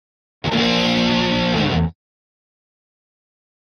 Guitar Heavy Metal Finale Chord 1